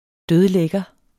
Udtale [ ˈdøðˈlεgʌ ]